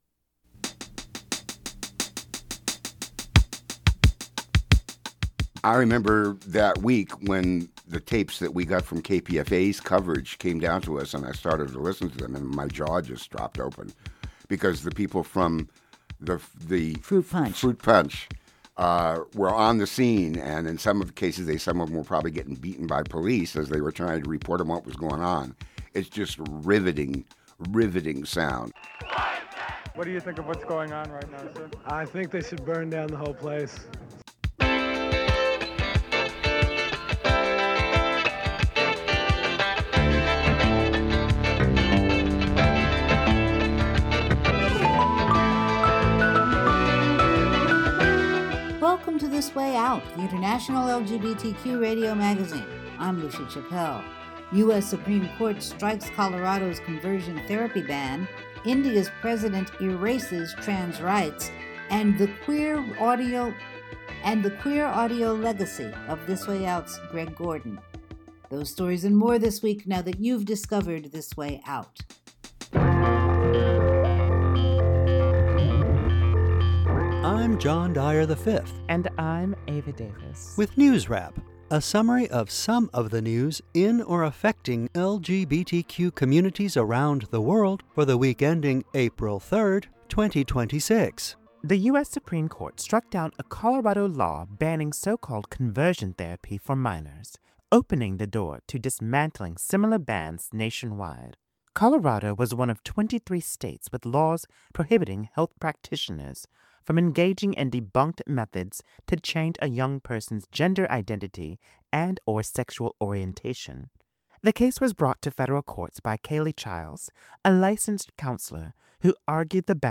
The International LGBTQ Radio Magazine